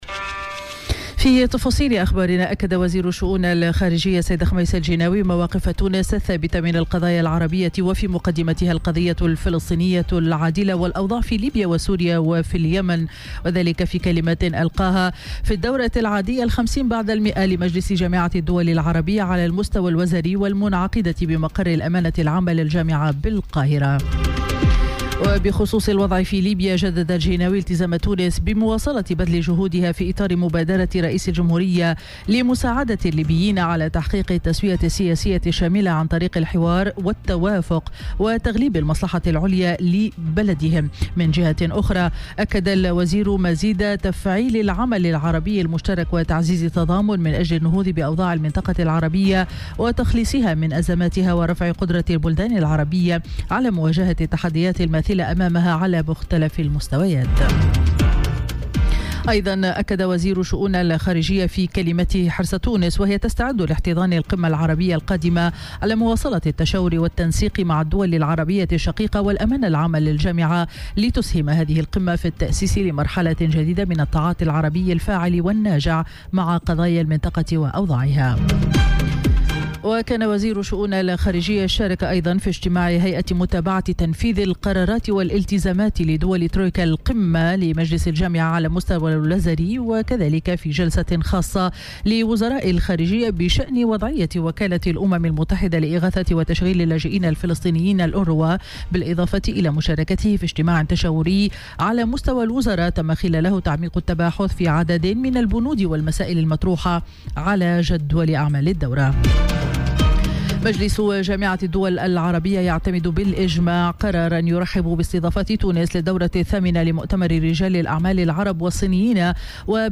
نشرة أخبار السابعة صباحا ليوم الإربعاء 12 سبتمبر 2018